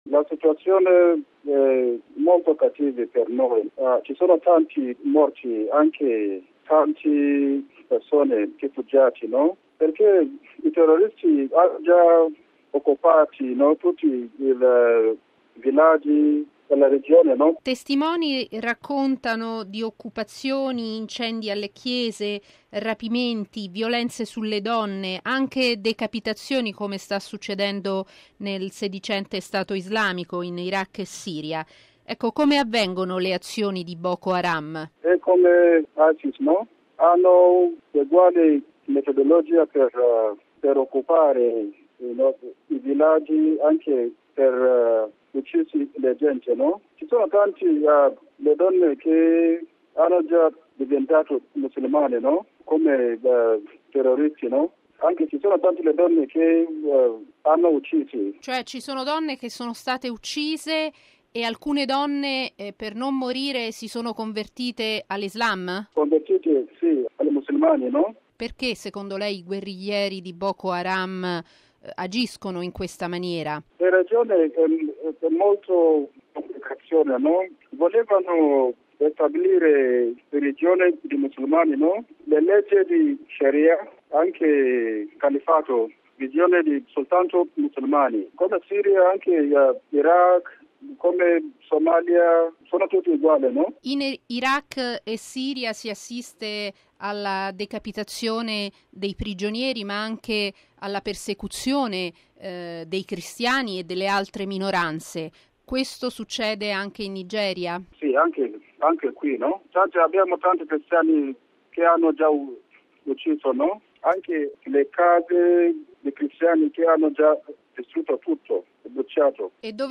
Violenze a Maiduguri, un religioso: Nigeria come Iraq e Siria